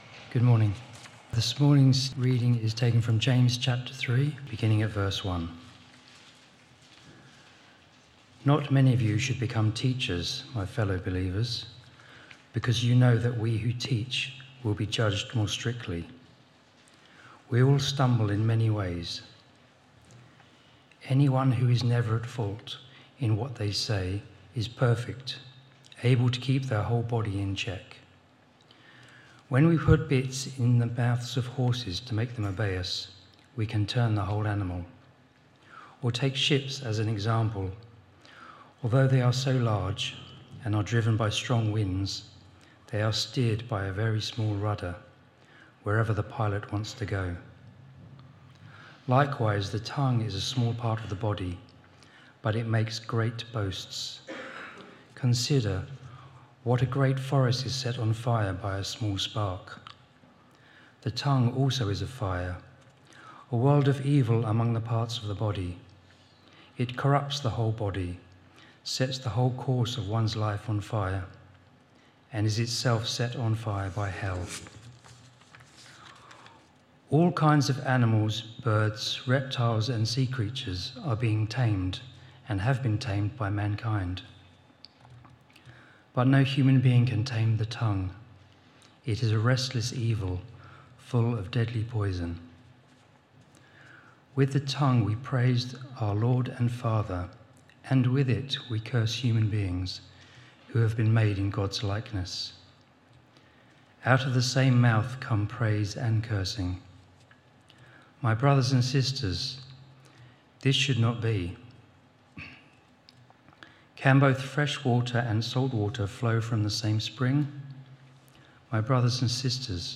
Media Library Media for Sunday Service on Sun 13th Jul 2025 10:00 Speaker
James: Faith in Action - Real Faith, Real Life. Theme: The Power of Words & Wisdom Sermon To find a past sermon use the search bar below You can search by date, sermon topic, sermon series (e.g. Book of the Bible series), bible passage or name of preacher (full or partial) .